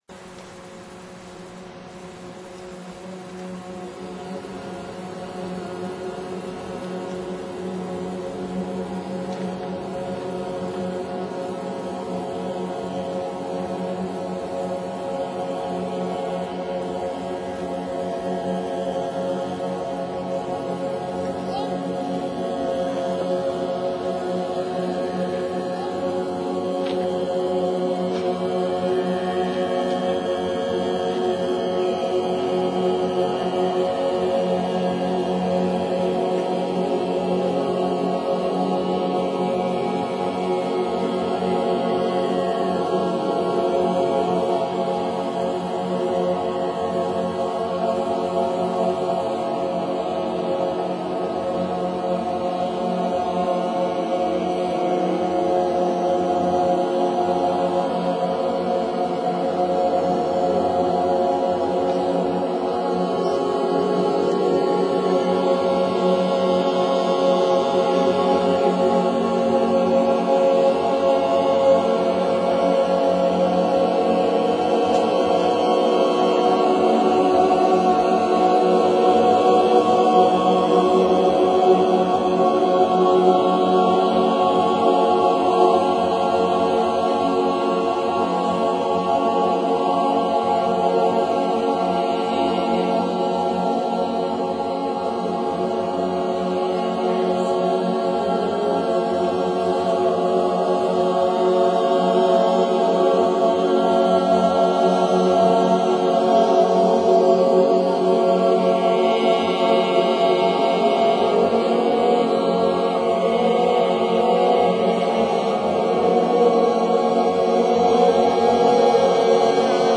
Veranstaltung in Planten un Blomen Hamburg
Tönen bei der Abschiedsfeier zum Abschluß des Erntedankrituals anhören:mandala.wma [1.205 KB]